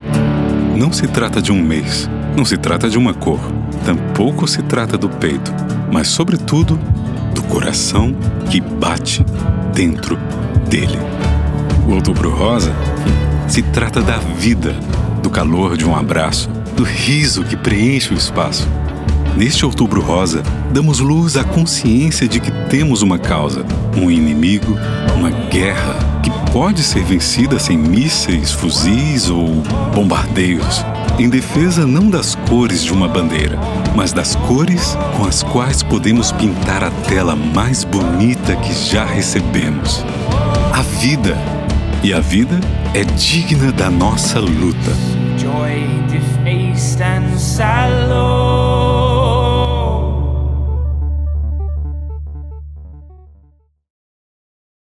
Ma voix est naturelle et professionnelle. Elle est souvent décrite comme crédible, veloutée et douce, inspirant confiance et calme à l'auditeur.
Microphone : Neumann TLM103
Cabine vocale acoustiquement isolée et traitée
BarytonBasseProfondBas